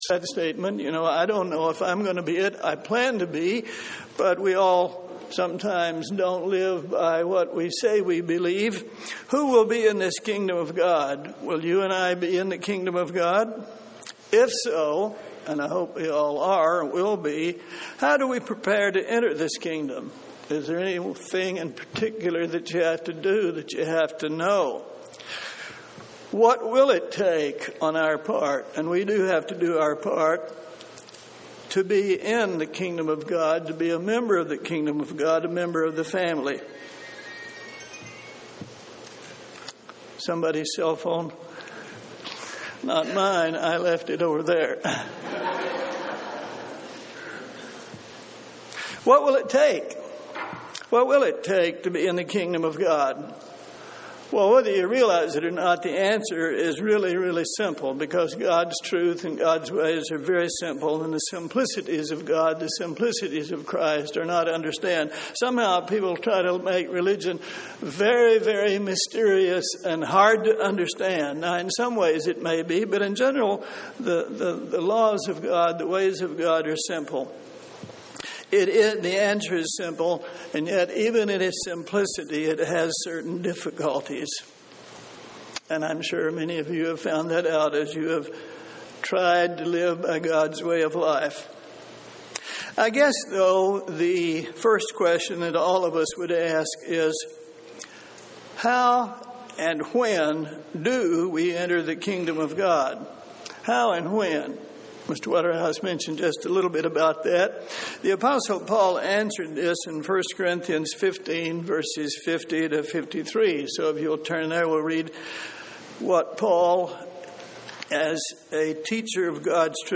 Who will be in the Kingdom of God, and how can you prepare to enter in the Kingdom? Learn in this Kingdom of God seminar what it will take to be in the Kingdom of God and member of God's family.
UCG Sermon Studying the bible?